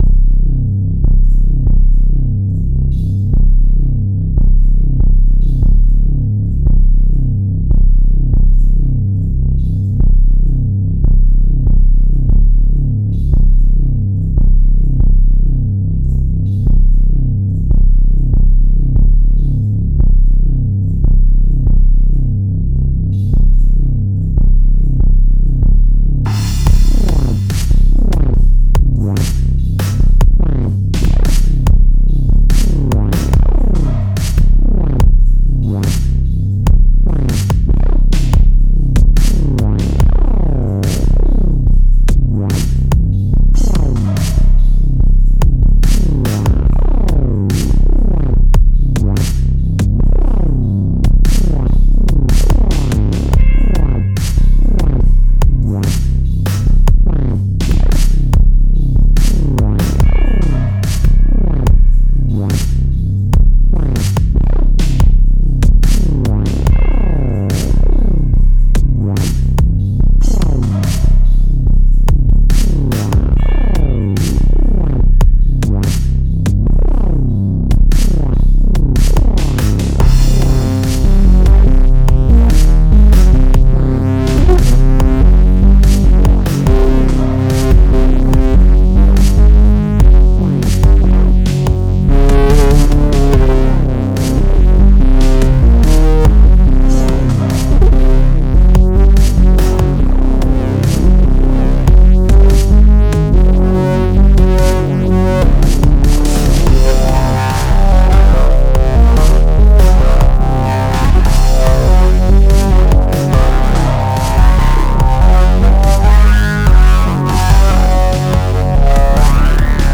bass line
weird
atmospheric
Bass Heavy modern Experimental weird stuff ...